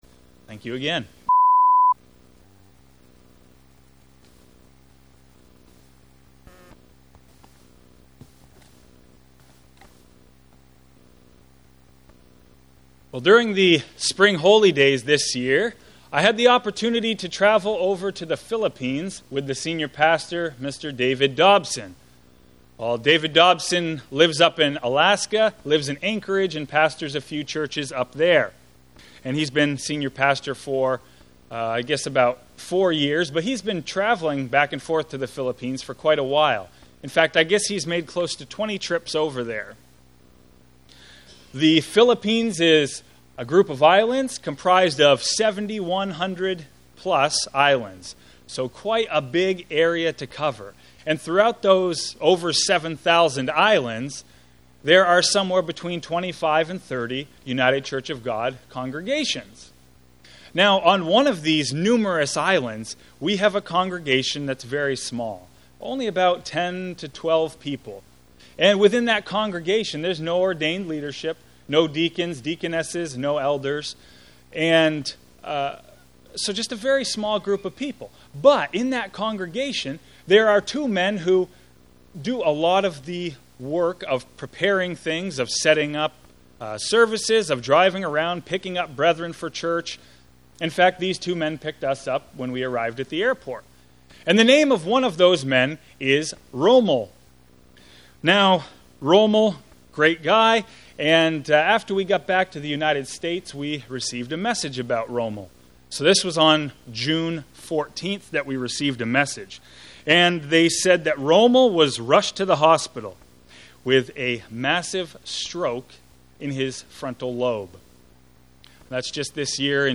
How do we go about claiming God's promises of healing, when does healing take place, and why does God sometimes choose not to heal? (The first minute or two of video was cut off.)
Given in Fargo, ND